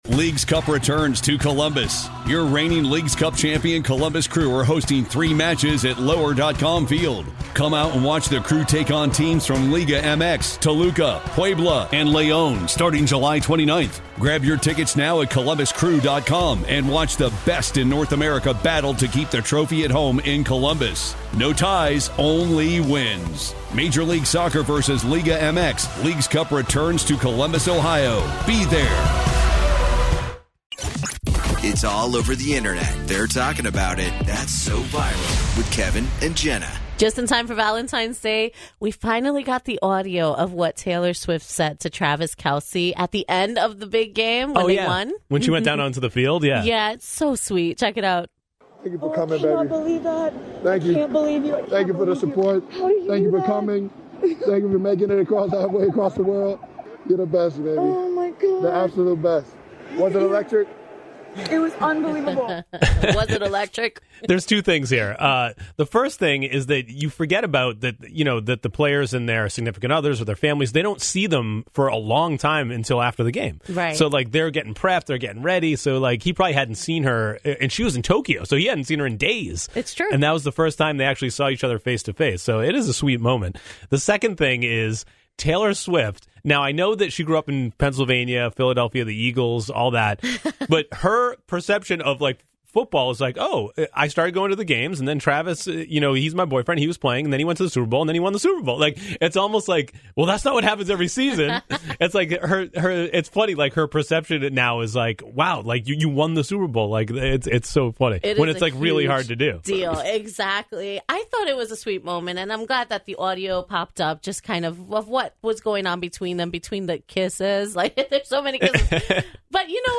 "Inside The NFL" released their mic'd up coverage from the Super Bowl, and we FINALLY hear what Taylor Swift and Travis Kelce said to each other during the postgame celebration on the field!